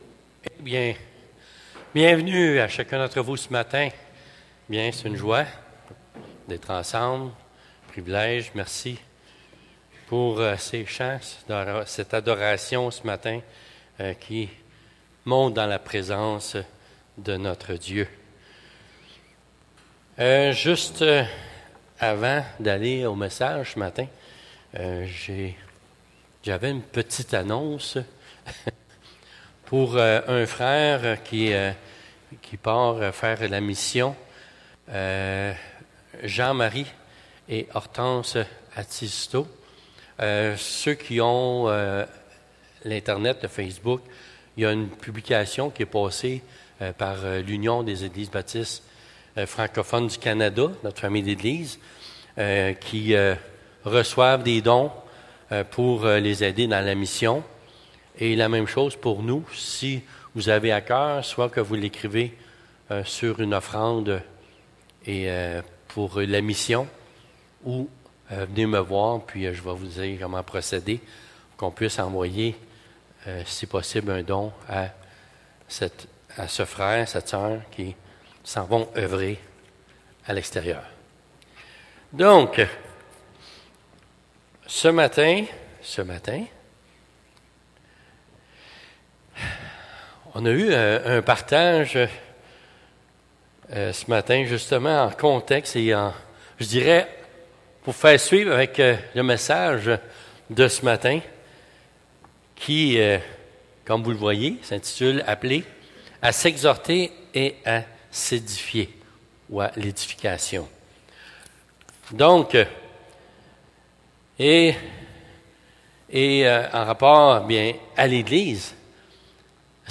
Prédication